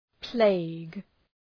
Προφορά
{pleıg}